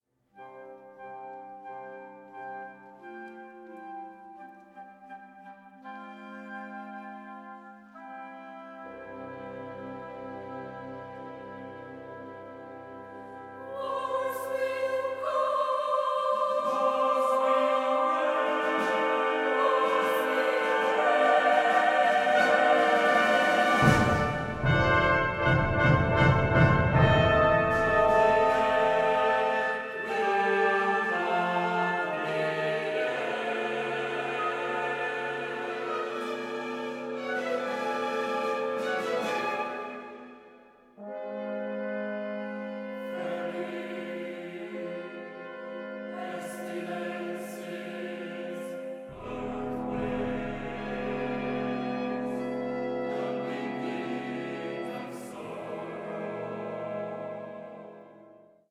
Stereo
an oratorio for chorus with orchestra